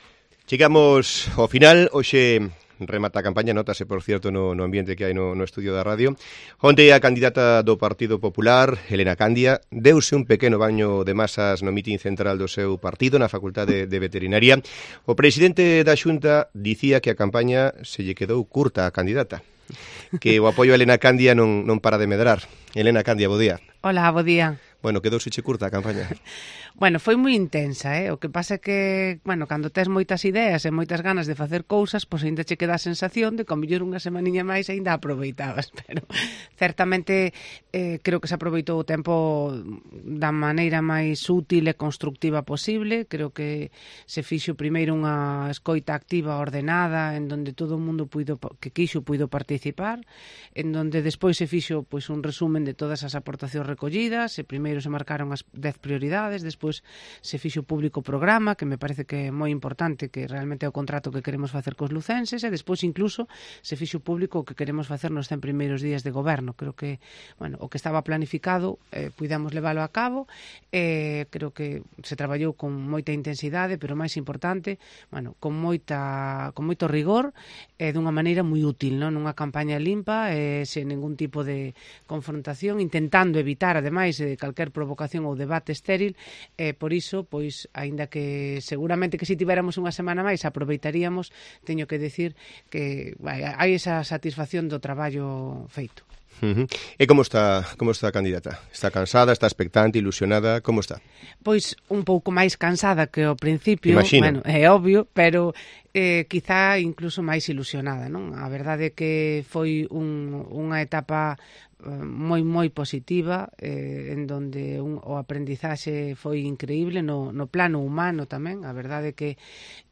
Entrevista a la candidata del PP a la Alcaldía de Lugo: Elena Candia